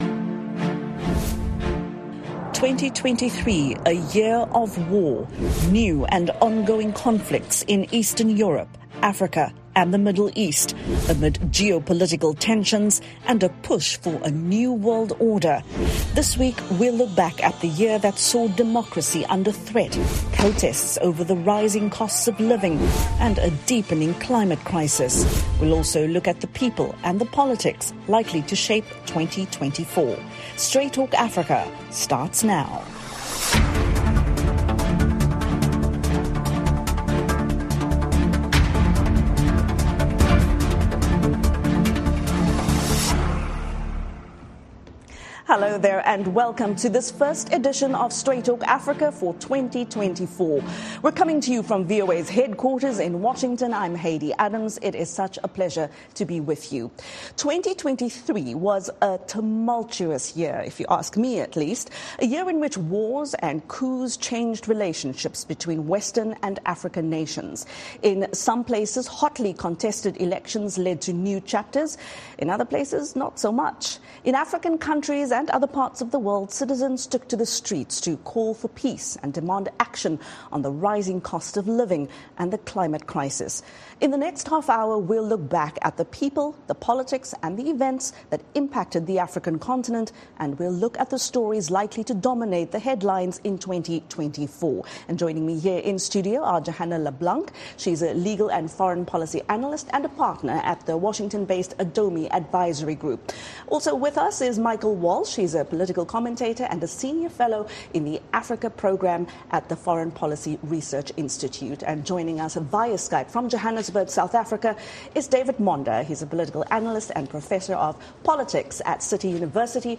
2023: Global Year in Review [simulcast]